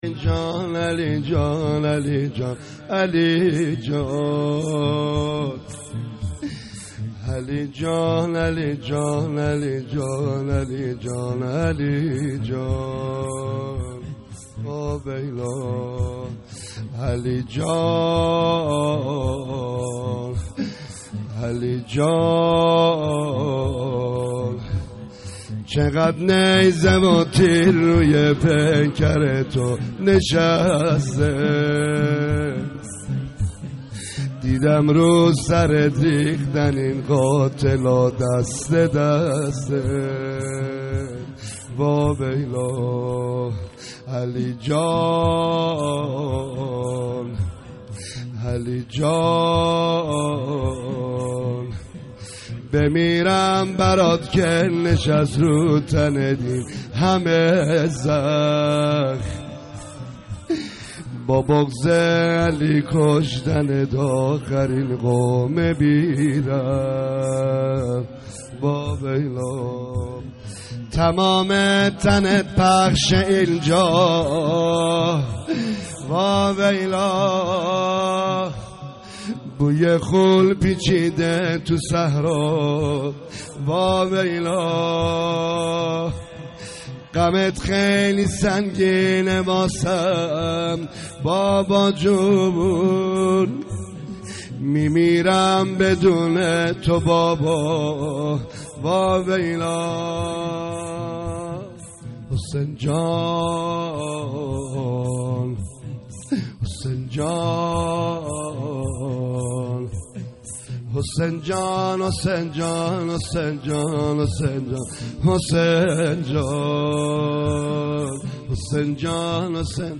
مداحی
در شب هشتم محرم 99
در هشتمین شب ماه محرم را می‌شنوید.